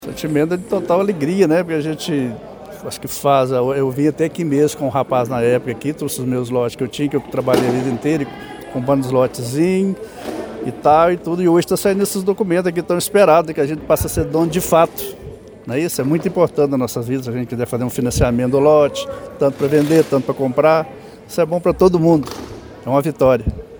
Relatos colhidos durante o evento traduzem o impacto social da medida: